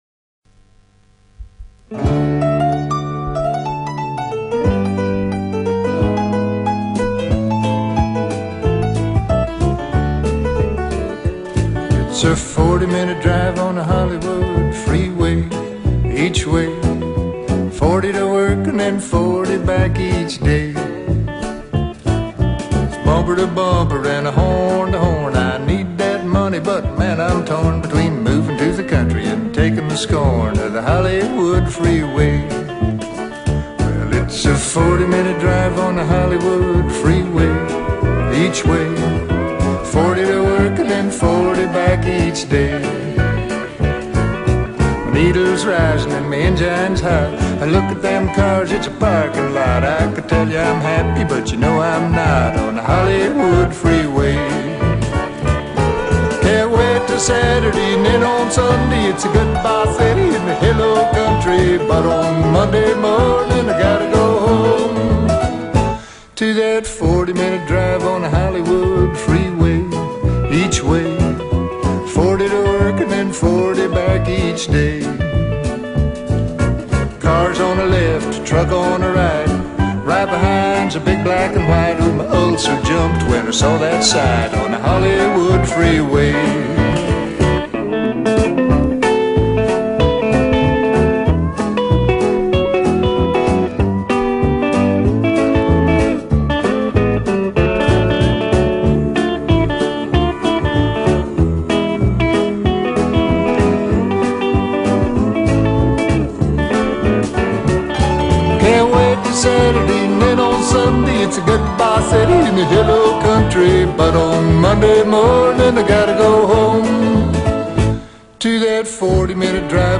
композитором и весьма неплохим исполнителем кантри-песен.